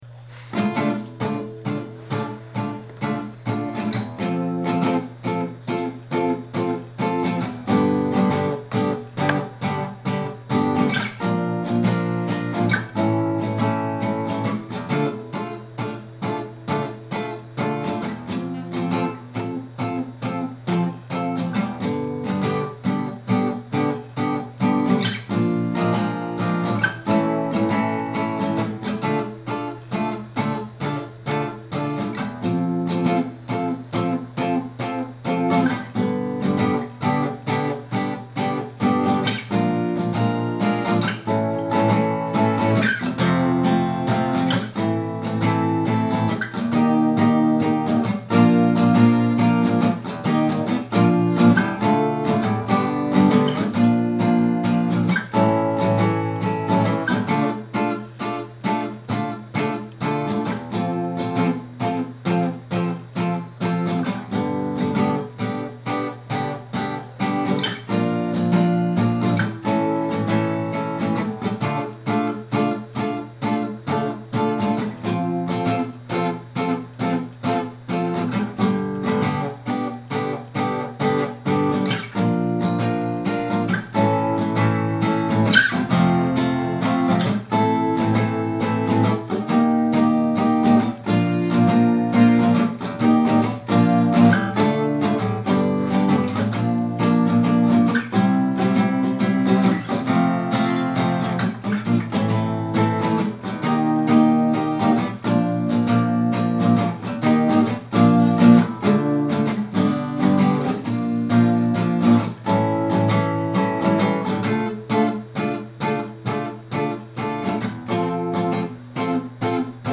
mini music-only demo
HtSILaET_-_music_demo.mp3